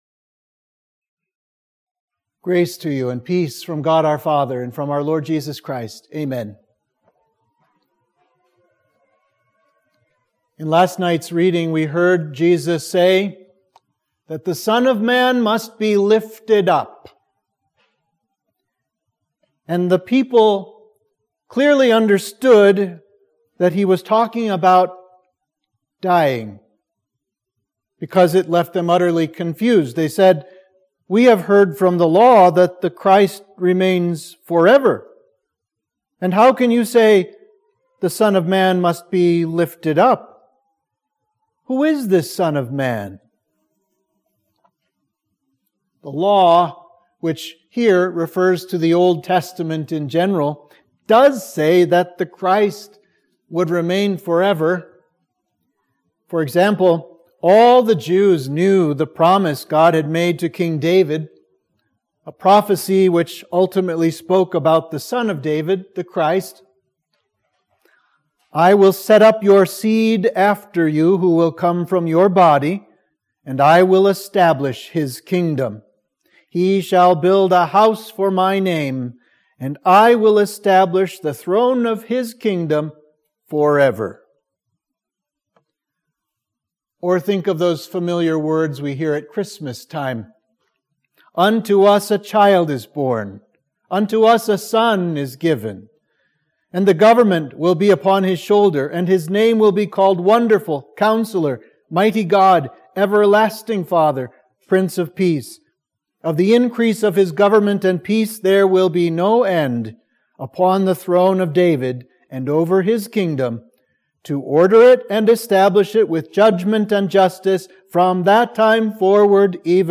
Sermon for Holy Tuesday